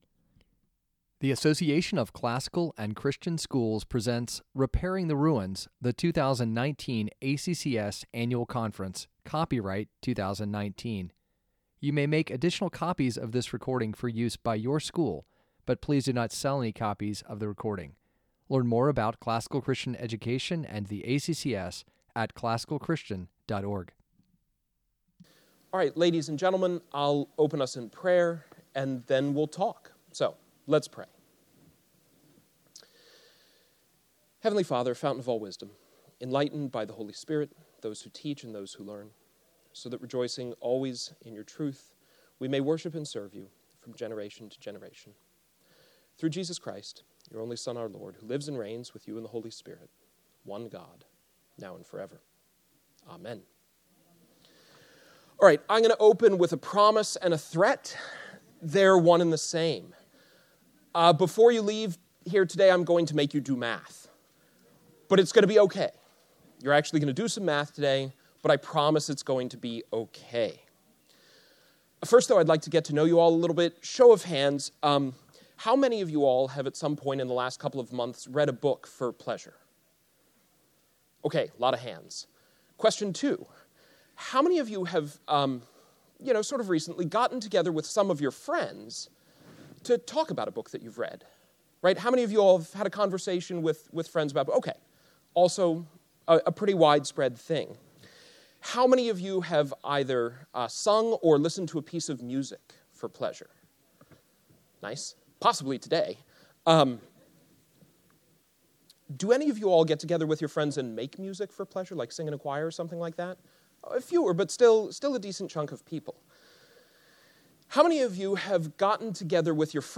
2019 Workshop Talk | 01:05:40 | 7-12, Math